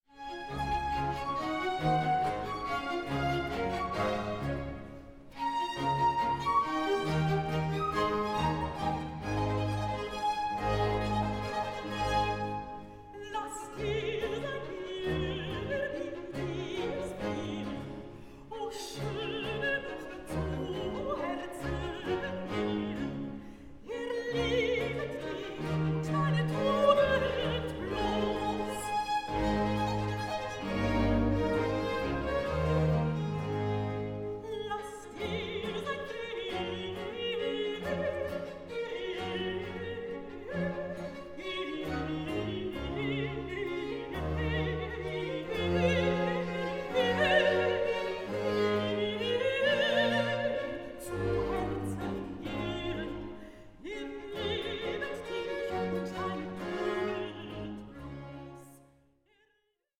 ORIENTAL BAROQUE OPERA